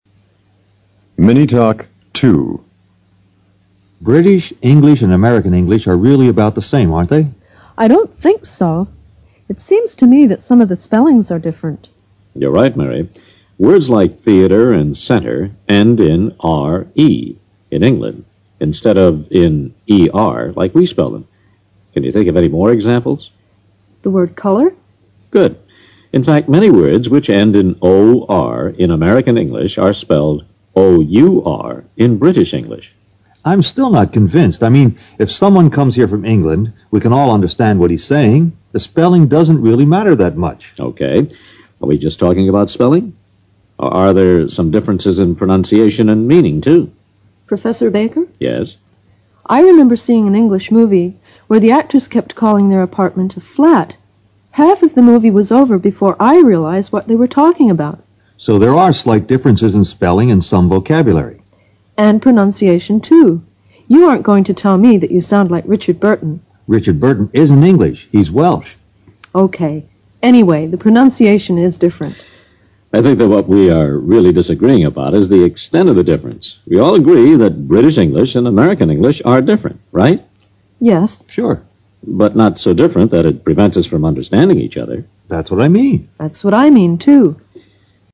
SECTION 1: LISTENING COMPREHENSION
PART C: MINI TALKS